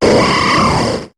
Cri d'Armaldo dans Pokémon HOME.